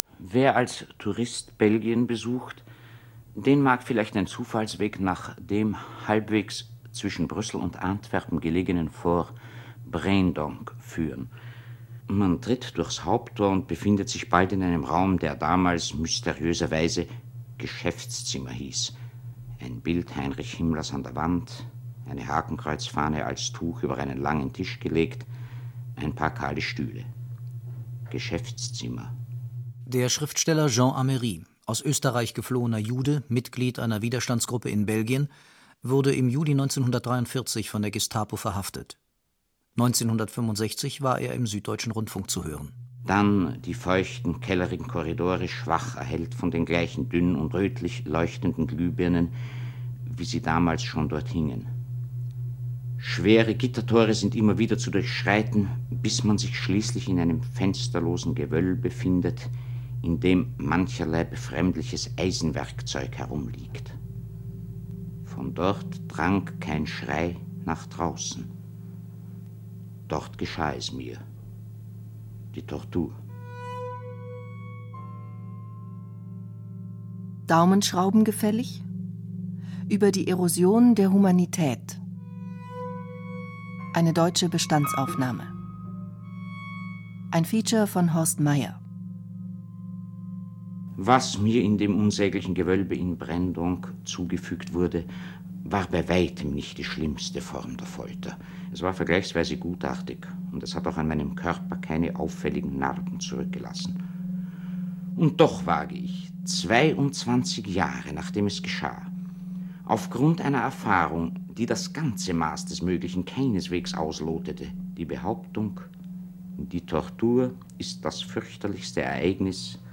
Feature Deutschlandfunk, 29.